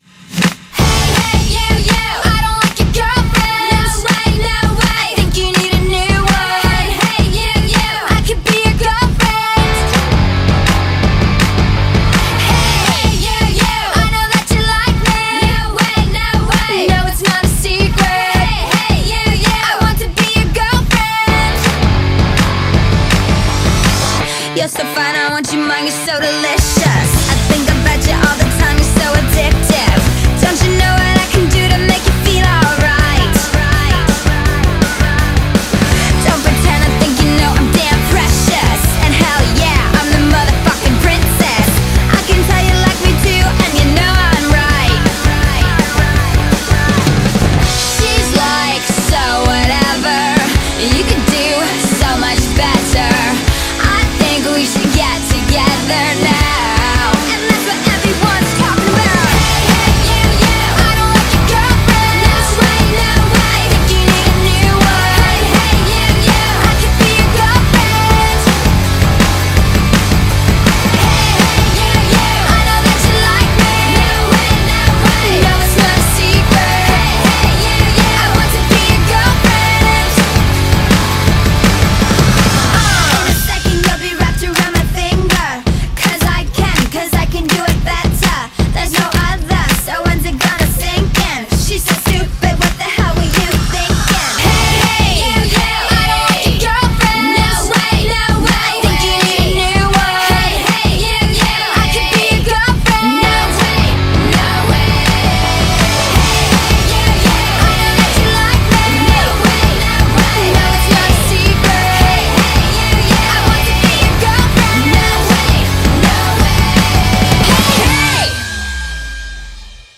BPM164
Audio QualityMusic Cut